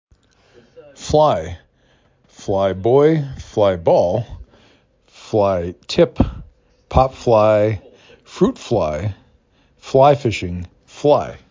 f l I